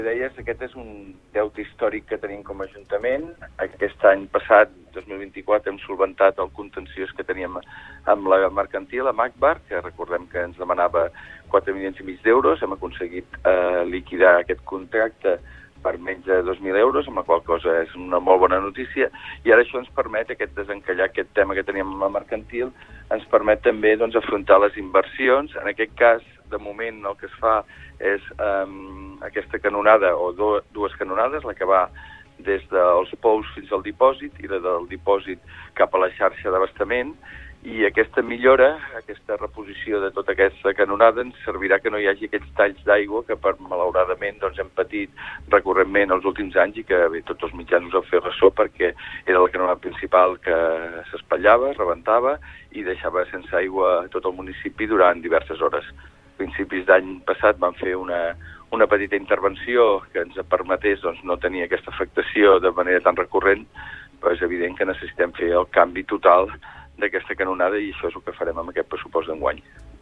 Supermatí - entrevistes
I per parlar dels pressupostos i d’aquestes inversions ens ha visitat al Supermatí l’alcalde del mateix municipi, Òscar Aparicio.